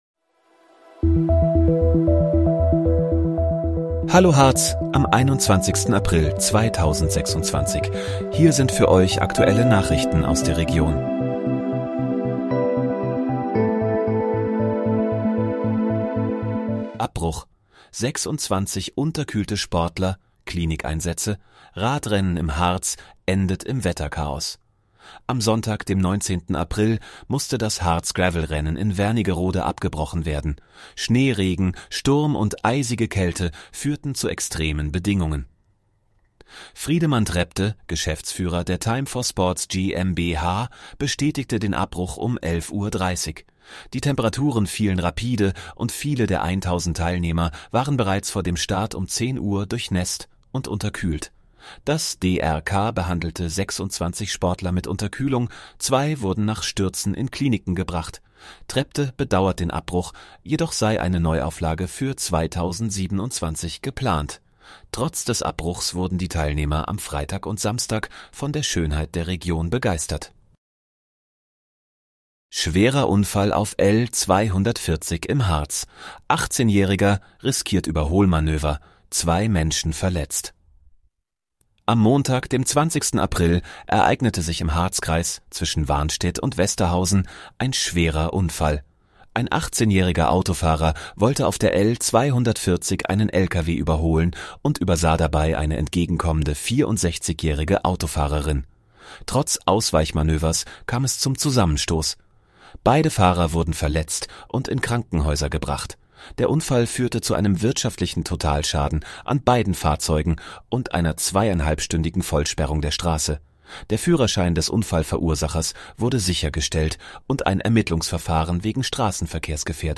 Hallo, Harz: Aktuelle Nachrichten vom 21.04.2026, erstellt mit KI-Unterstützung